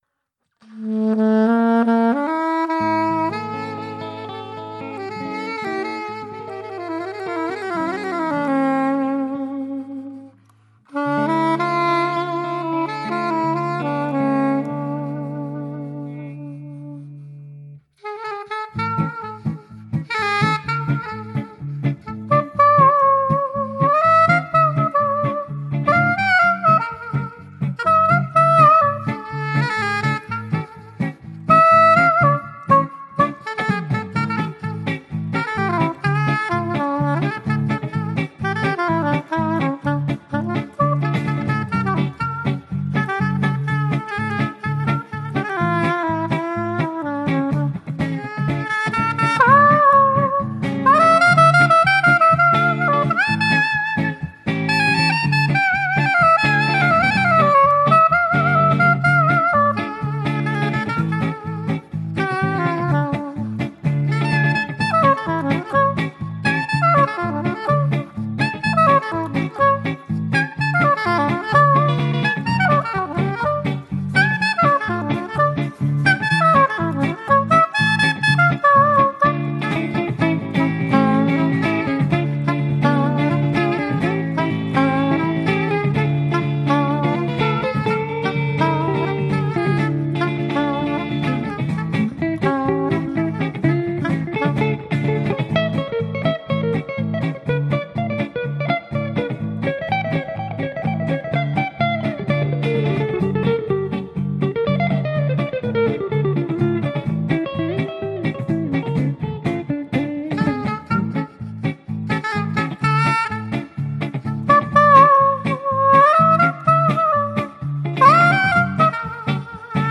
Les Yeux Noir (Duo) » Musikagentur berlinklang